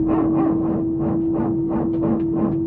airbus_ptu.wav